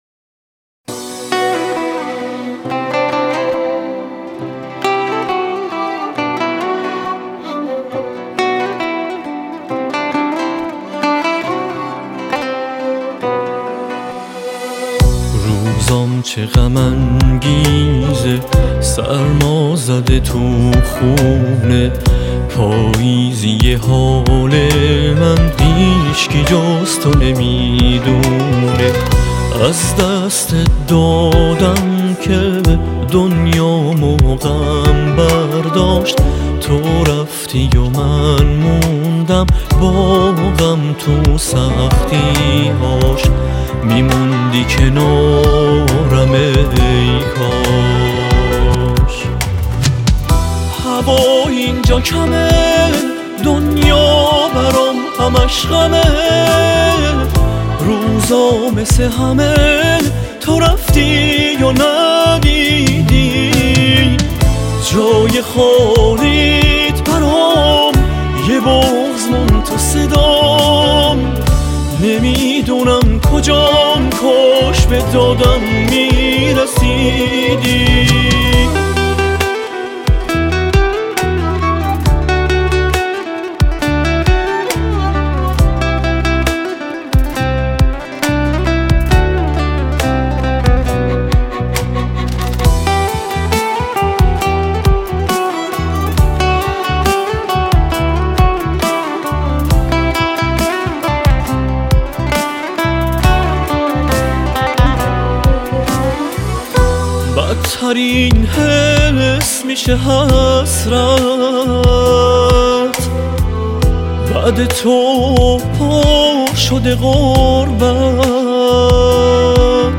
این آهنگ در سبک ژانر اهنگ پاپ خوانده شده است.